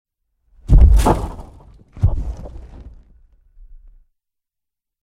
horror
Monster Footsteps